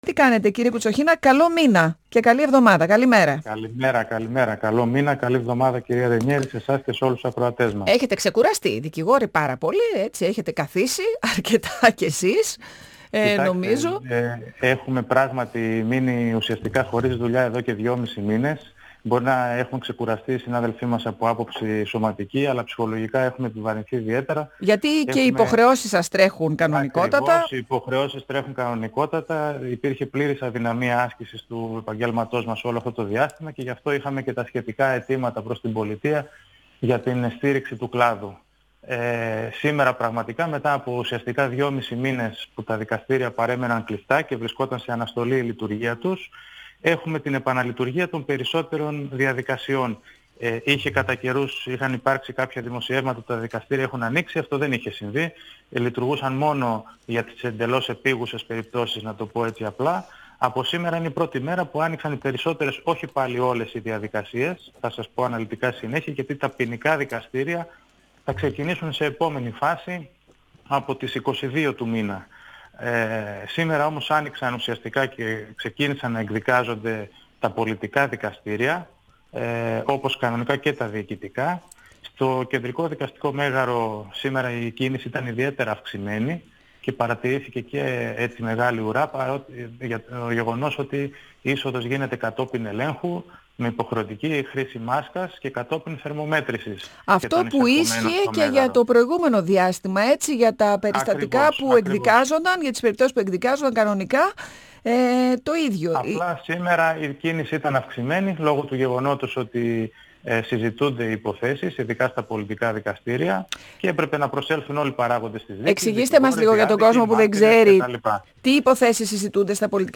Σε περιπτώσεις συναινετικής απόφασης περί αναβολής, αυτό θα μπορεί να γνωστοποιείται με ηλεκτρονικό τρόπο επίσης. 102FM Συνεντεύξεις ΕΡΤ3